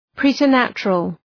Προφορά
{,pri:tər’nætʃərəl}